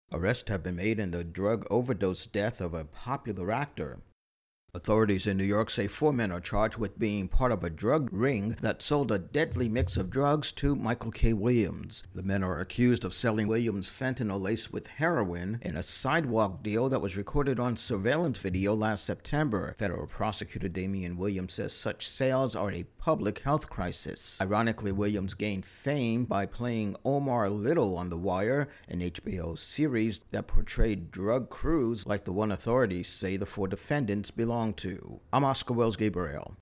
Intro+wrap on arrests made in actor Michael K, Williams' drug overdose death.